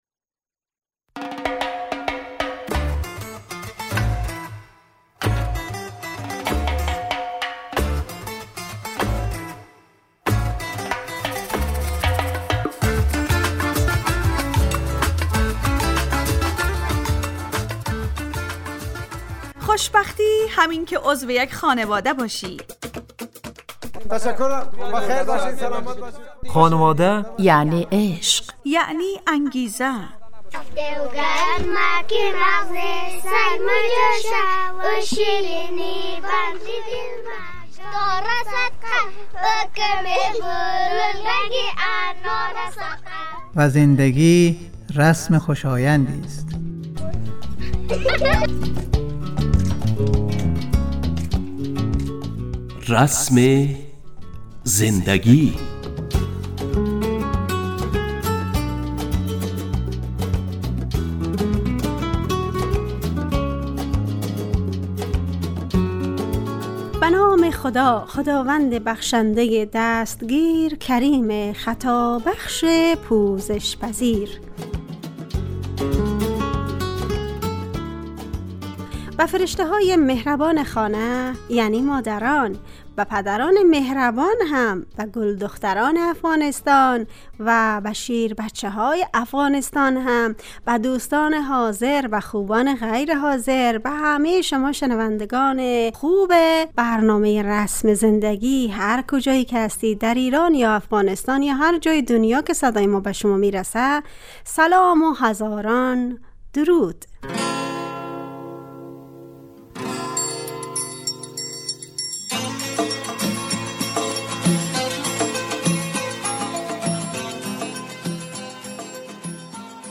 برنامه خانواده رادیو دری